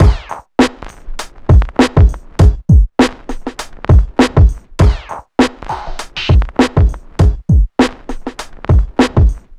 nu funky 100bpm 02.wav